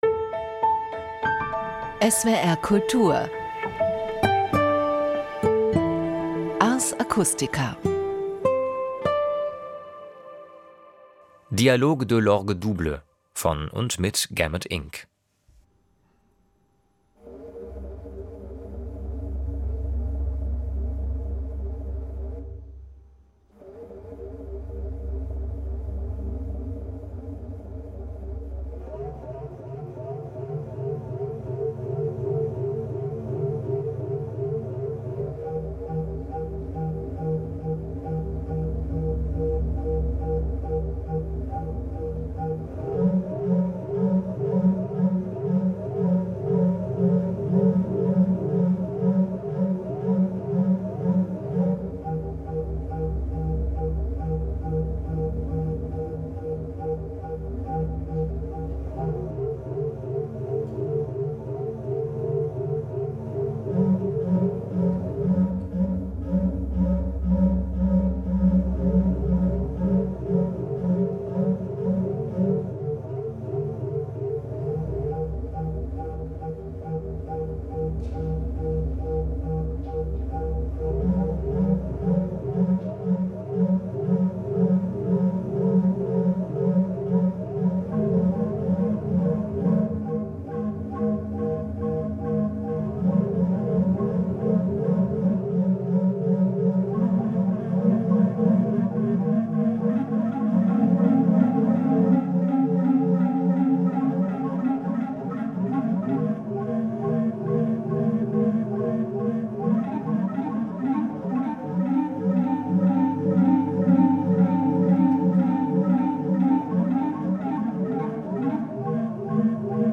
Orgeltradition mit Klangsynthese